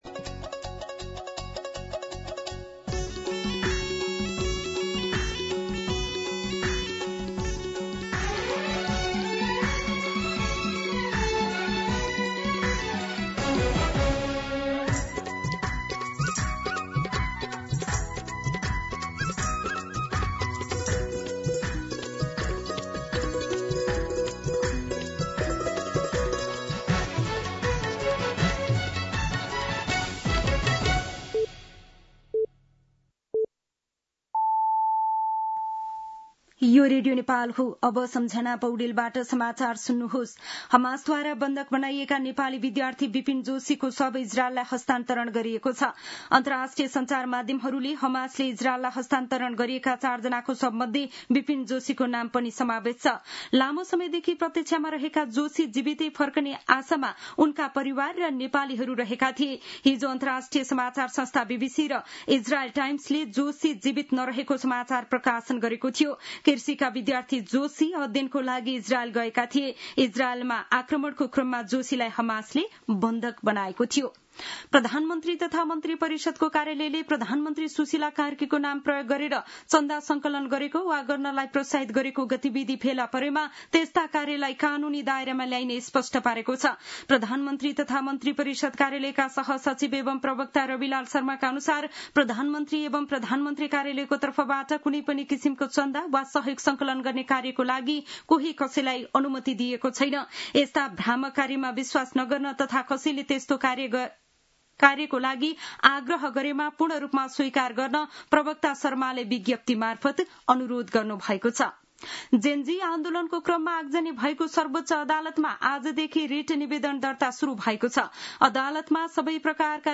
An online outlet of Nepal's national radio broadcaster
मध्यान्ह १२ बजेको नेपाली समाचार : २८ असोज , २०८२